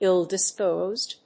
アクセントíll‐dispósed
ill-disposed.mp3